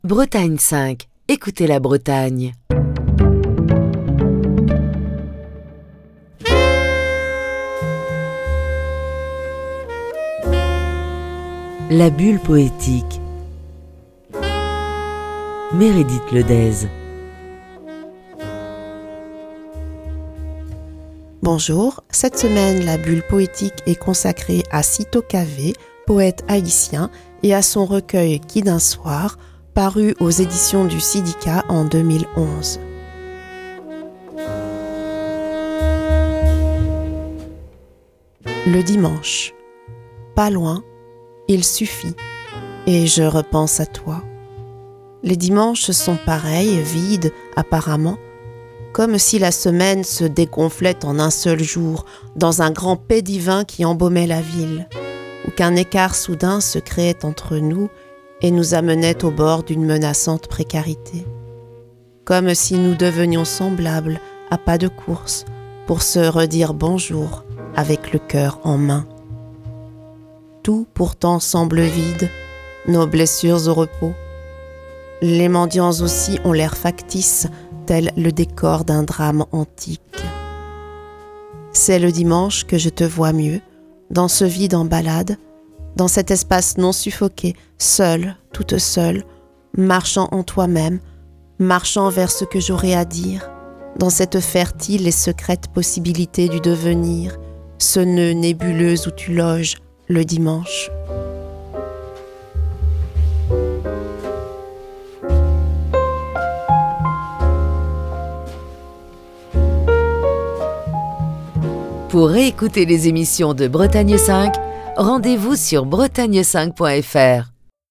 lit quelques poèmes du poète haïtien Syto Cavé, extraits de son recueil "Qui d'un soir ?", paru aux éditions du CIDIHCA en 2011.